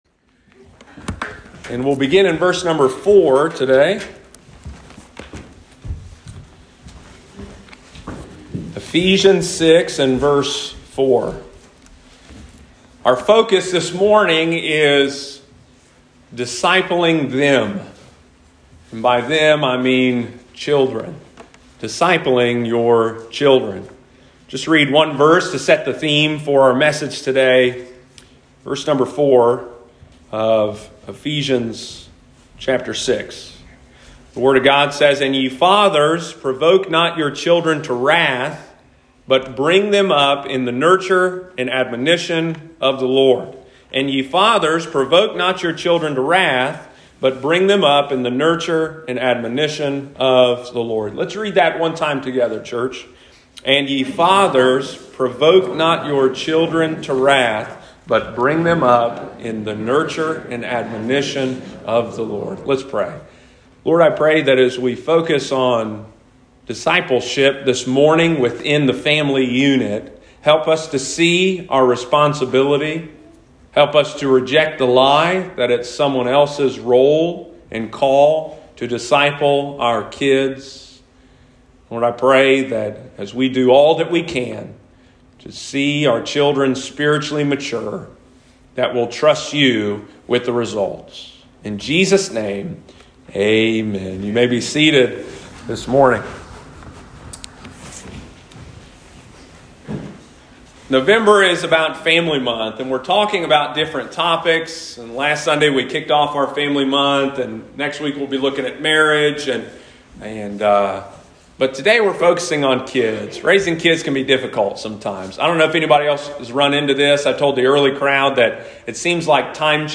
Sunday morning, November 14, 2021.